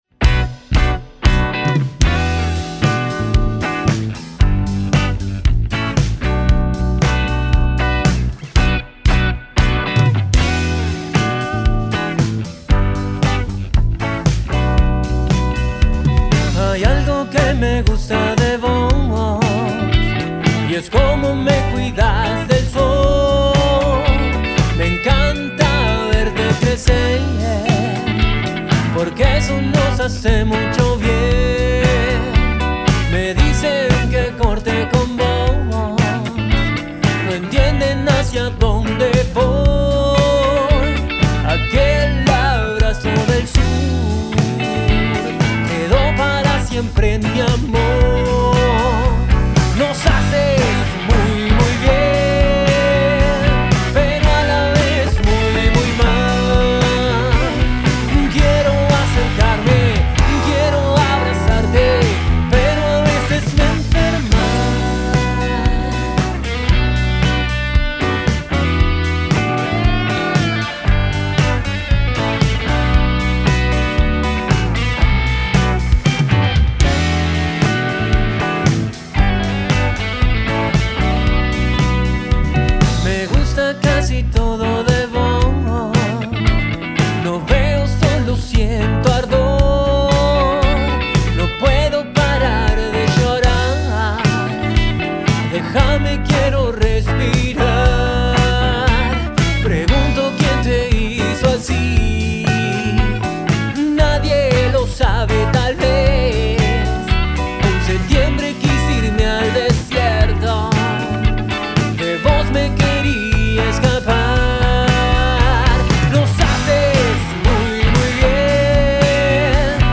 Grabada en vivo el 1 de junio de 2025